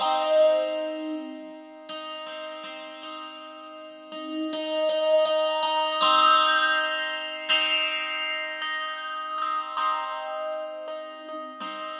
ElectricBerimbau4_80_D#.wav